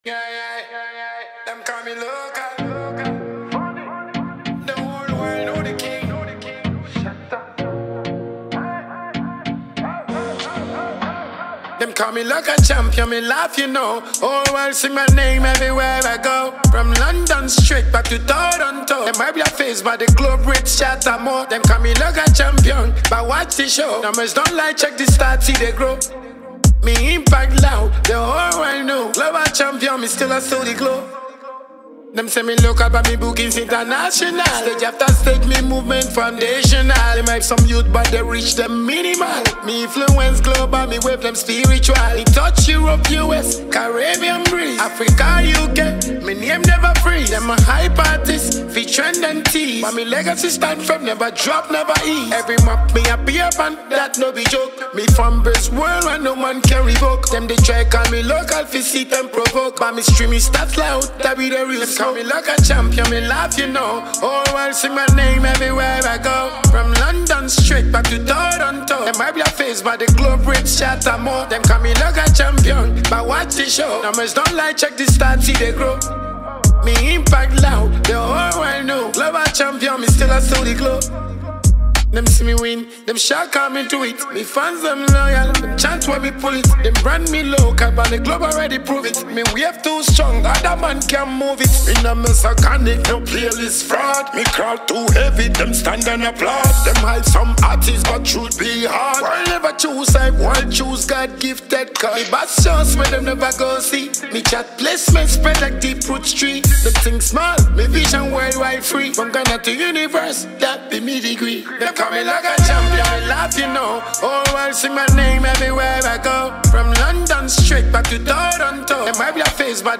Ghanaian dancehall
bold and energetic anthem